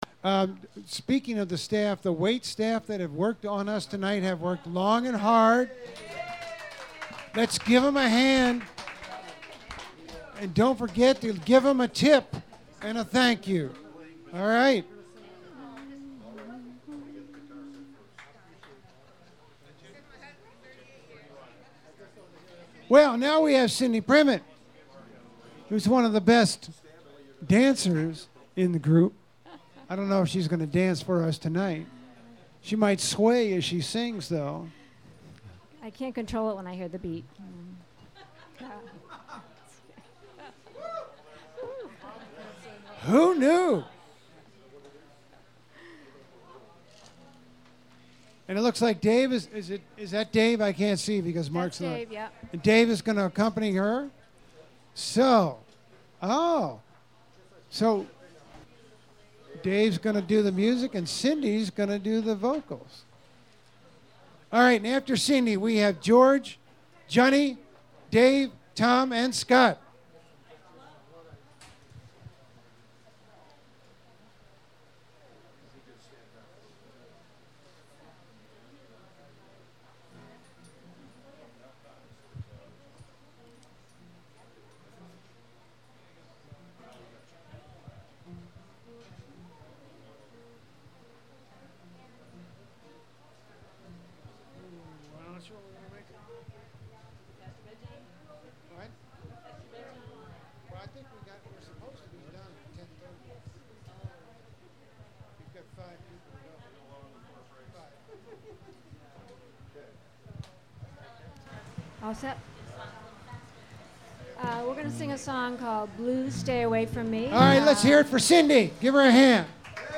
Files with a title starting raw or Untitled have only been track-level volume adjusted and are not joined, clipped, equalized nor edited.
raw Main Streets Market and Cafe Concord Open Mike, 7/6/15.